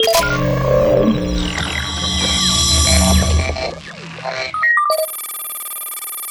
medscan.wav